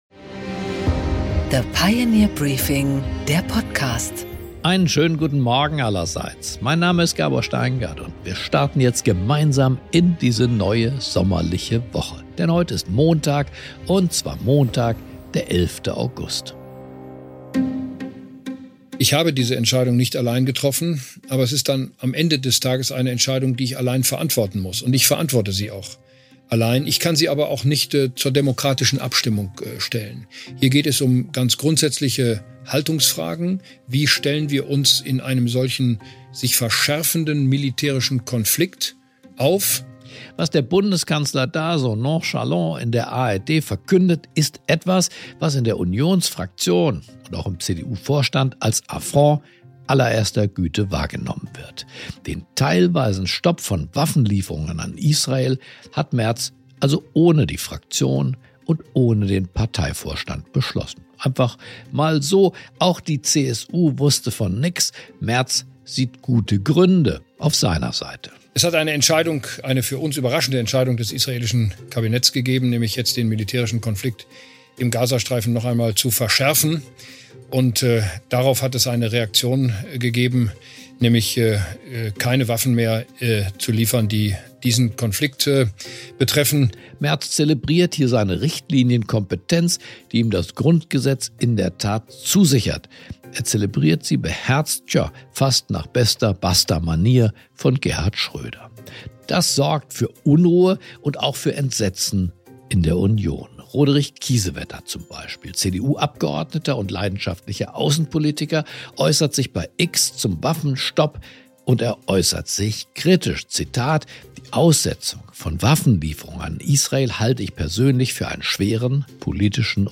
Gabor Steingart präsentiert das Pioneer Briefing
Im Gespräch: Prof. Volker Wieland, Ökonom, rechnet im Gespräch mit Gabor Steingart mit der deutschen Bürokratie ab - auch in der Schuldenpolitik.